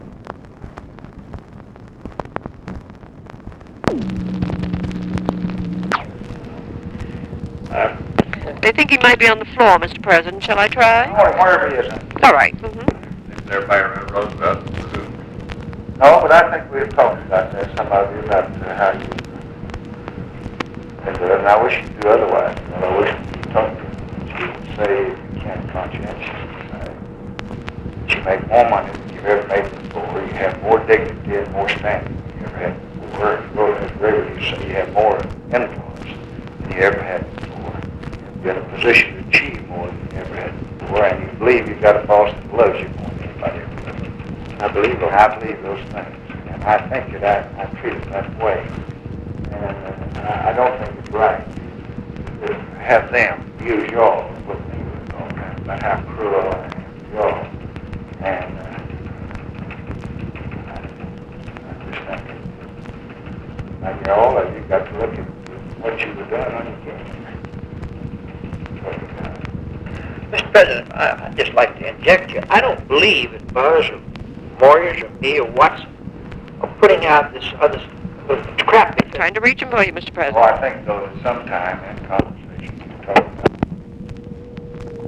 LBJ TALKS TO SEVERAL PEOPLE ABOUT PRESS LEAKS FROM WH STAFF, DUTIES OF WH STAFFERS, WHILE WAITING FOR CALL TO UNNAMED PERSON (JOHN MCCLELLAN?) TO BE PLACED
Conversation with OFFICE CONVERSATION
Secret White House Tapes